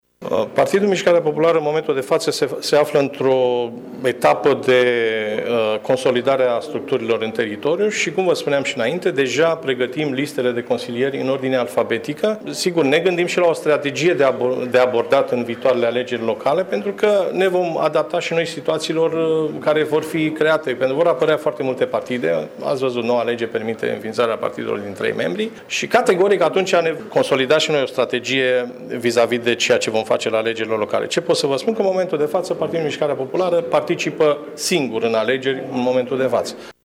La congresul de alegeri a fost prezent deputatul de Alba, Clement Negruţ, care a spus că PMP se pregăteşte deja pentru alegerile de anul viitor: